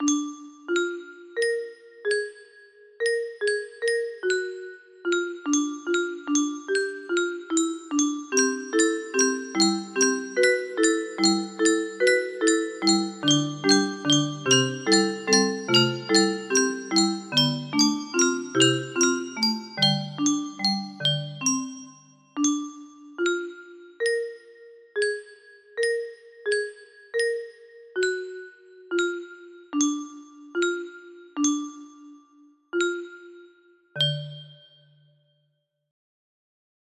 Tune 1 - Buyronics music box melody
Yay! It looks like this melody can be played offline on a 30 note paper strip music box!